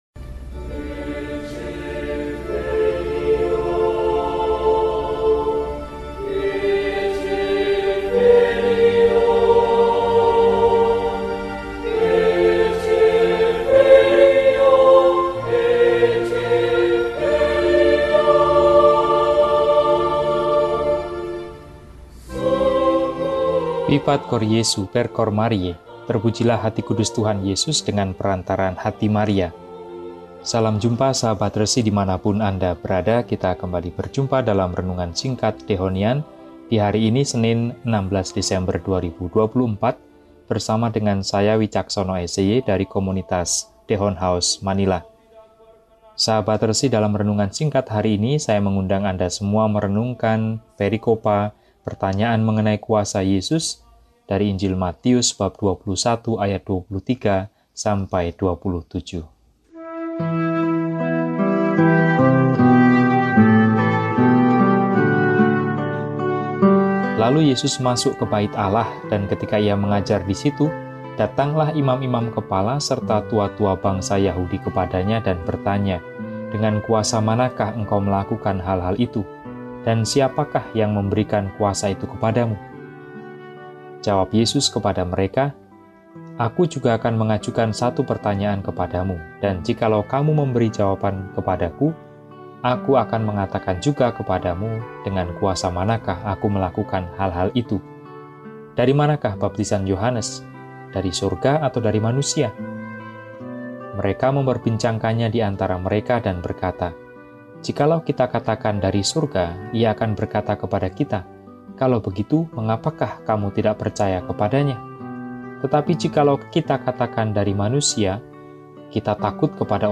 Senin, 16 Desember 2024 – Hari Biasa Pekan III Adven (Sore: Novena Natal Hari Pertama) – RESI (Renungan Singkat) DEHONIAN